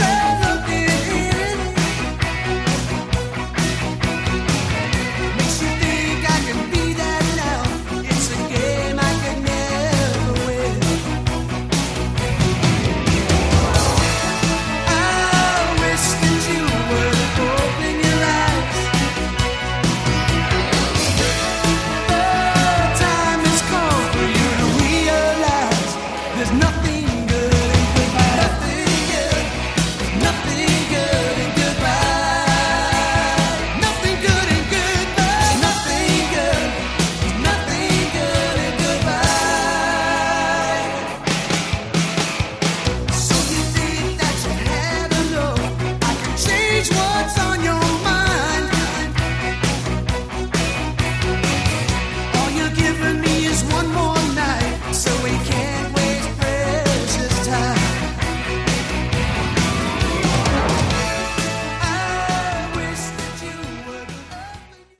Category: AOR
lead and backing vocals, keyboards